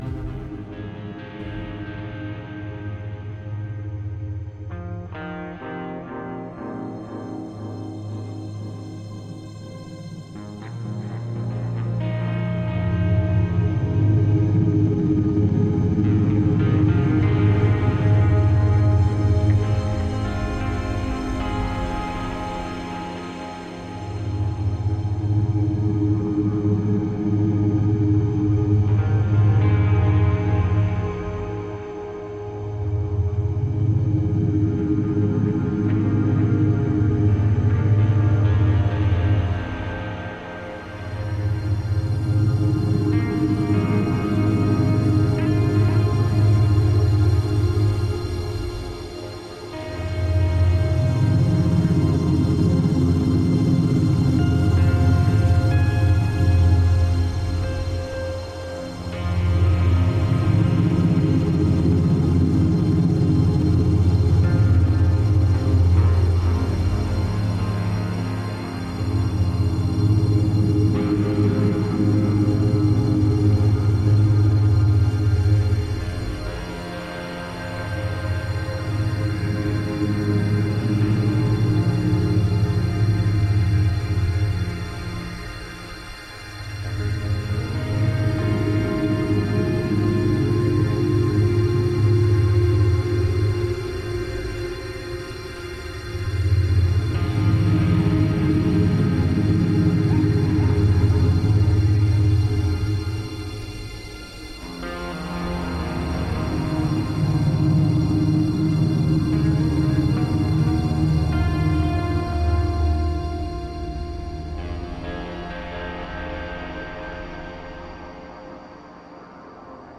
Tagged as: Electronica, Experimental